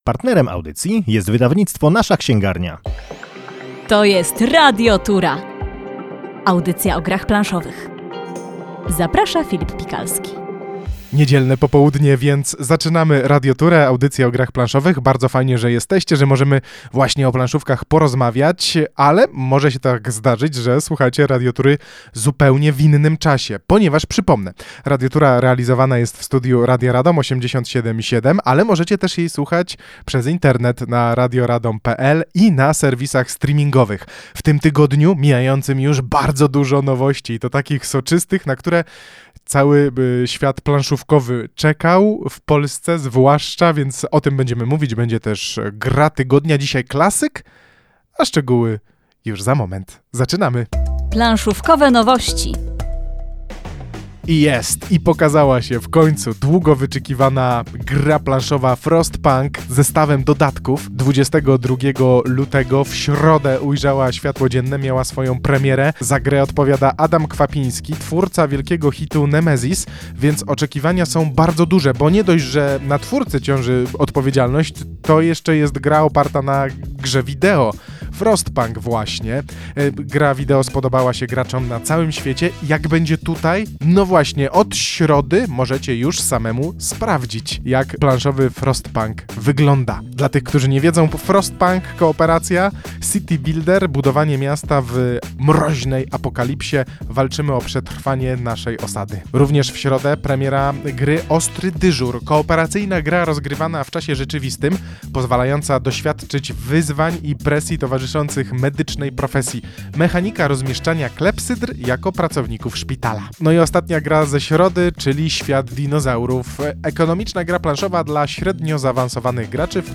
Radiotura – audycja radiowa o grach planszowych.